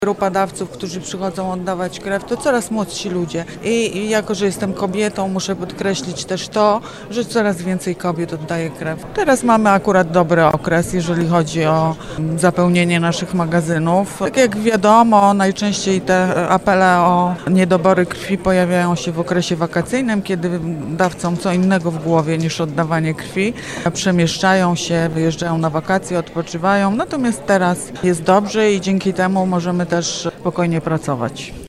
Z udziałem wojewody Władysława Dajaczka w Filharmonii Zielonogórskiej odbyła się Wojewódzka Uroczystość z okazji 60-lecia Honorowego Krwiodawstwa Polskiego Czerwonego Krzyża.